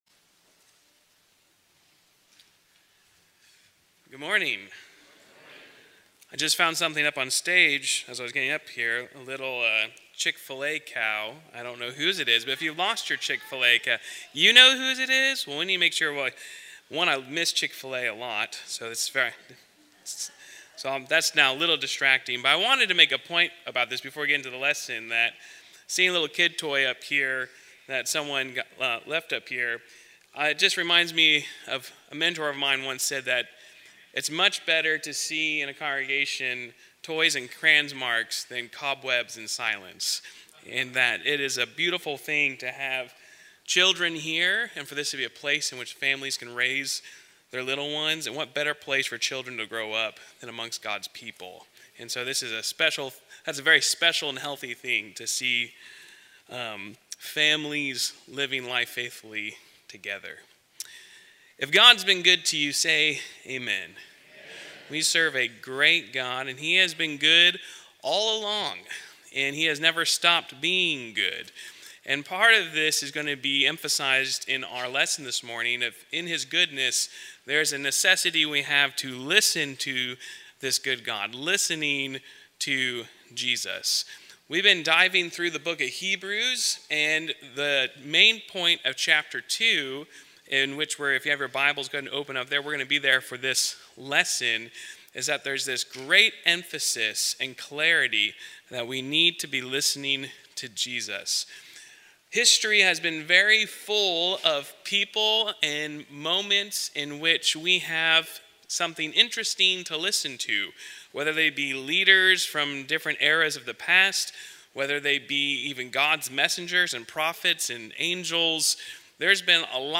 Home Resources Sermons